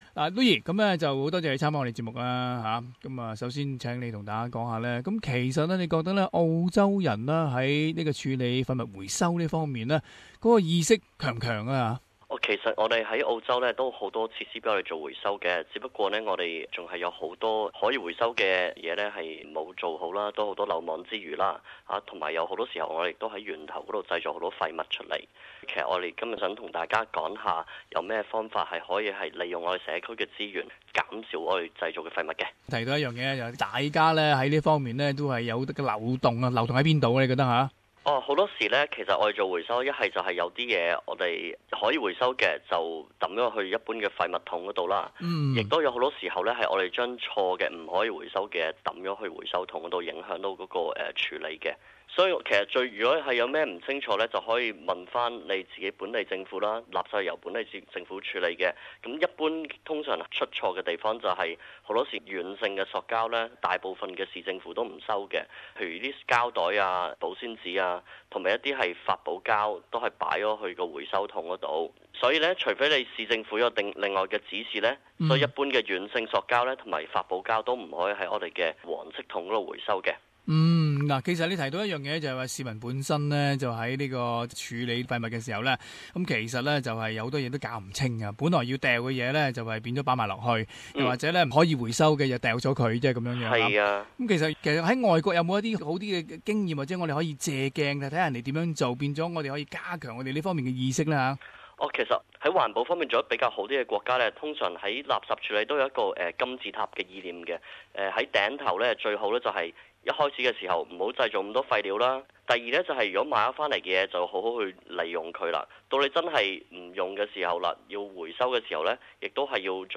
【社团专访】社区废物及资源循环再用